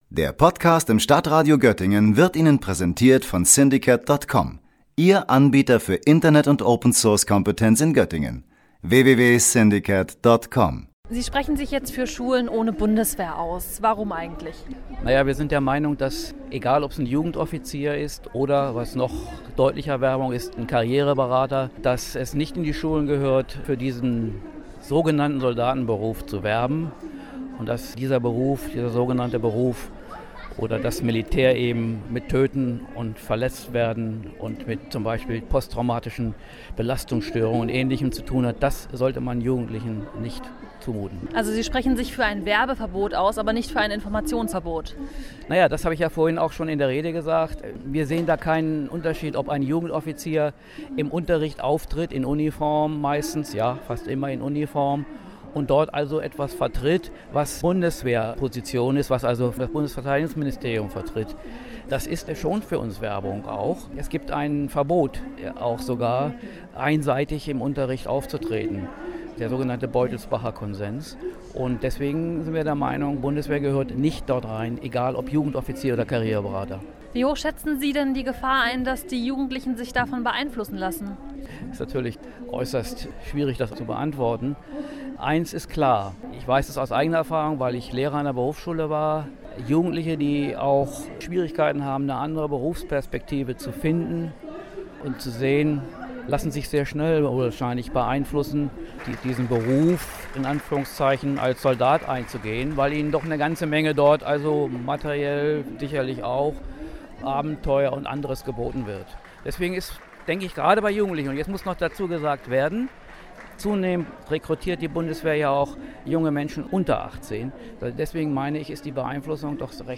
Verschiedene Gruppen und Organisationen wie Attac, die Anti-Atom-Initiative, die Deutsche Friedensgesellschaft oder der DGB Südniedersachsen haben am Samstag eine Kundgebung am Nabel in Göttingen unterstützt.